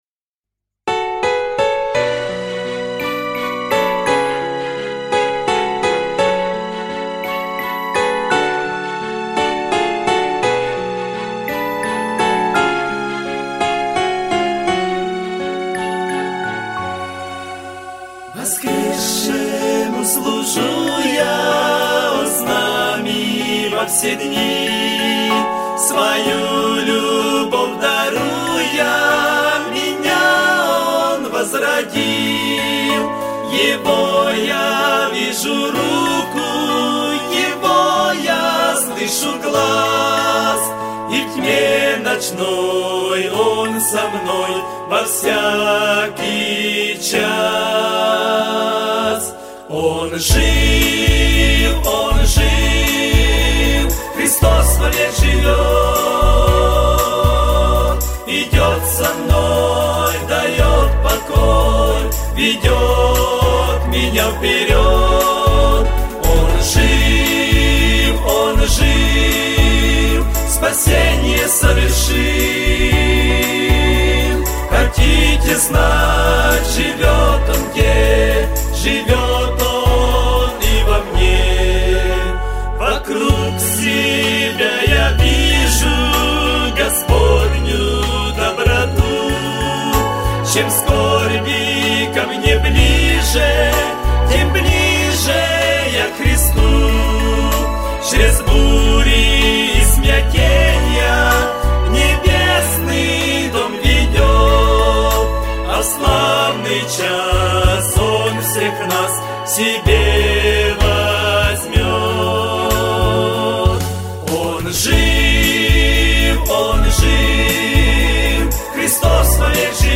598 просмотров 1178 прослушиваний 96 скачиваний BPM: 116